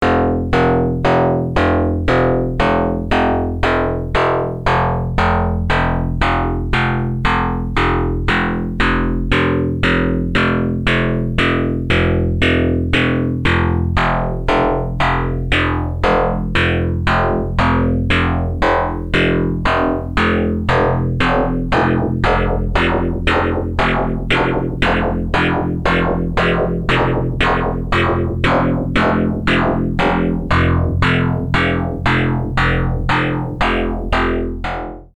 software filter on pattern waves, sound example from the MonoLadder
After 8 seconds you can hear that the filter starts moving and is modulated with an LFO.
patternfilter-demo1.mp3